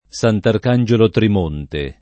Sant’Arcangelo [Sant ark#nJelo] top. — solo gf. divisa, in generale: Sant’Arcangelo Trimonte [